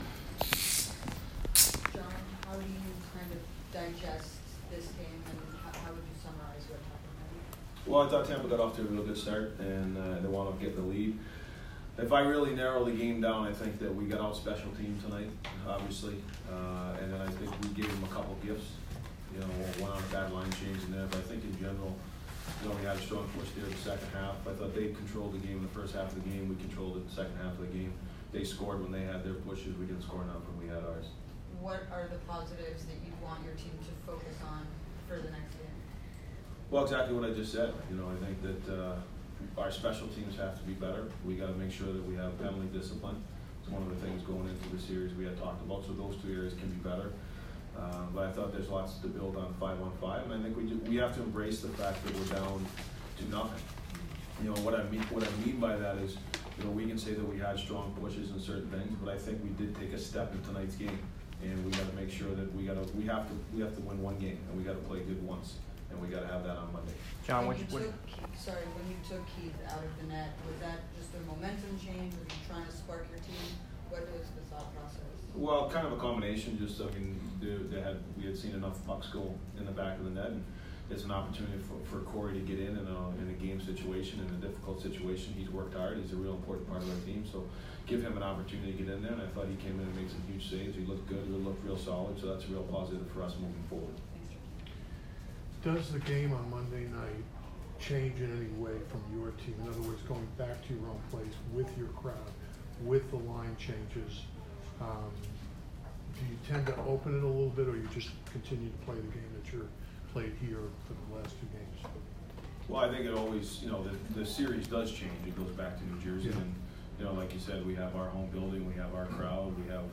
Devils head coach John Hynes post-game 4/14